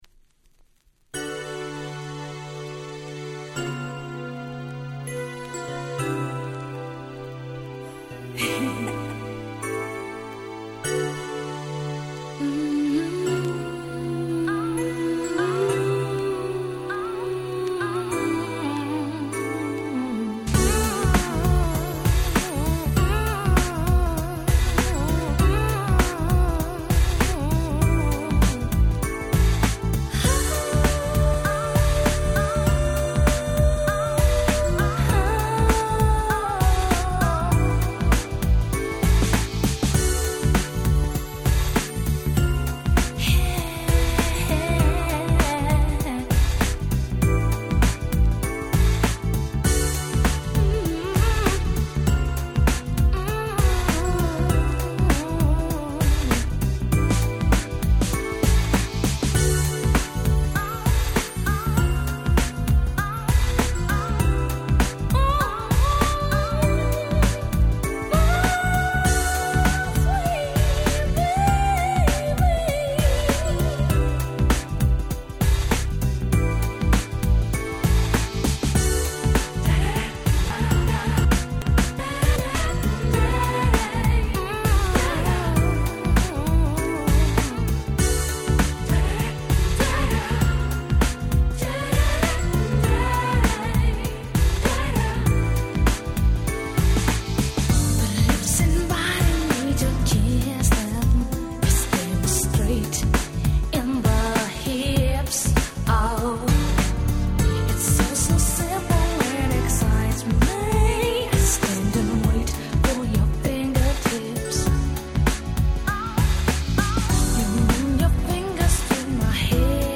93' Nice UK R&B !!